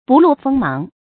不露鋒芒 注音： ㄅㄨˋ ㄌㄡˋ ㄈㄥ ㄇㄤˊ 讀音讀法： 意思解釋： 鋒：兵刃；芒：植物的尖刺。